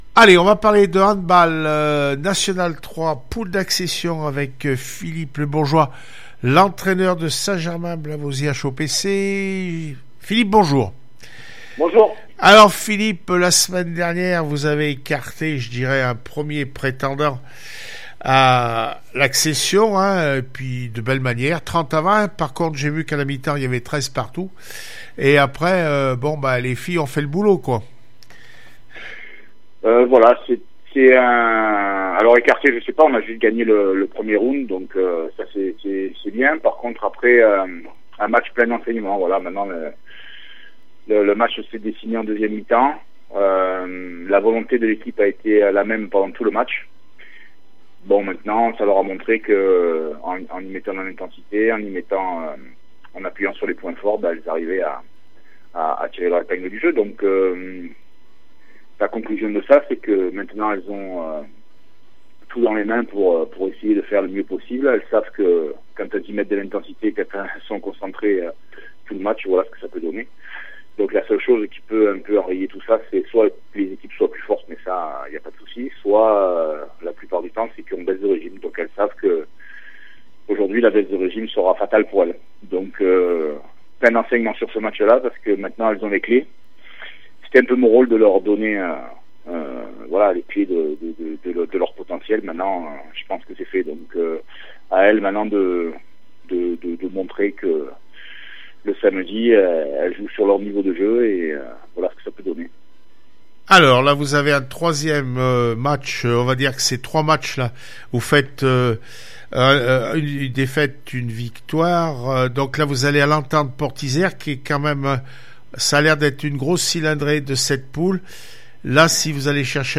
15 février 2020   1 - Sport, 1 - Vos interviews, 2 - Infos en Bref   No comments